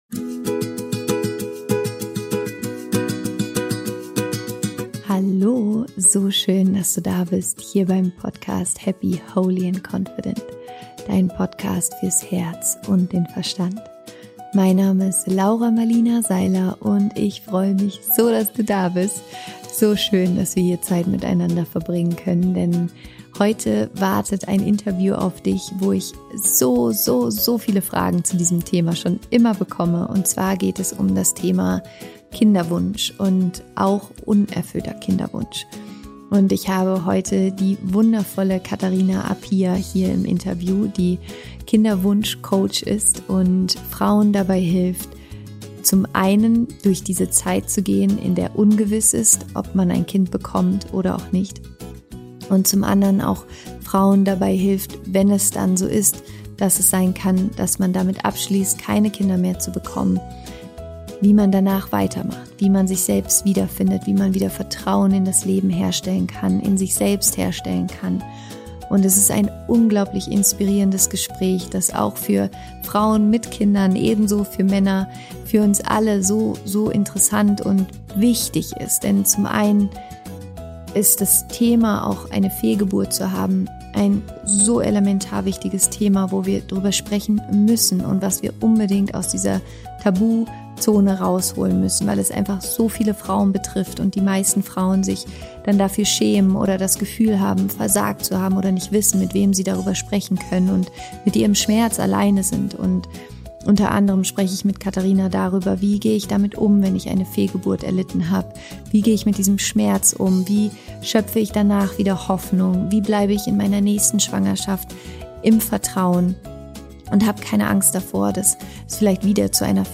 Im Interview spricht sie darüber, wie wichtig es ist, zu trauern, aber auch immer wieder ins Vertrauen zu kommen und zu heilen. Sie erzählt, wie ihr Dankbarkeit und Meditation dabei geholfen haben zu erkennen, dass man auch ohne Kind wertvoll und vollständig ist.